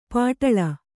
♪ pāṭaḷa